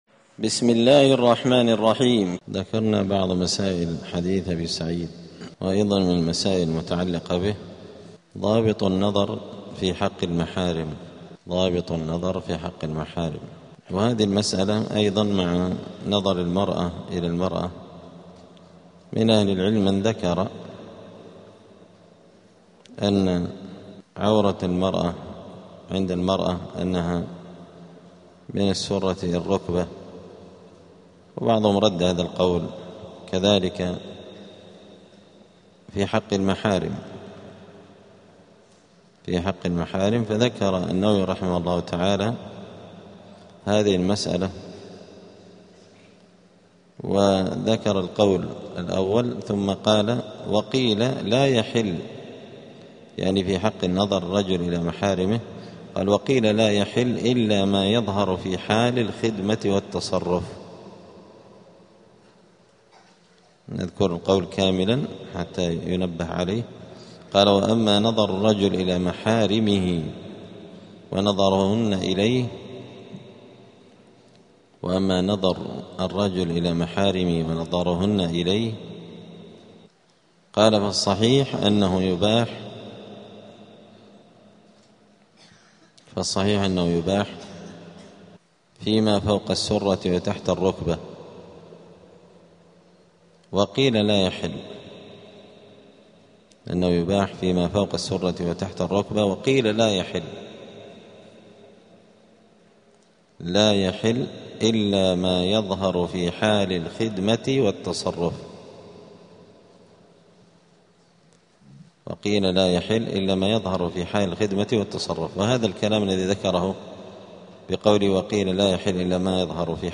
دار الحديث السلفية بمسجد الفرقان قشن المهرة اليمن
*الدرس الثاني والستون بعد المائة [162] باب ستر العورة {ظابط النظر في حق المحارم}*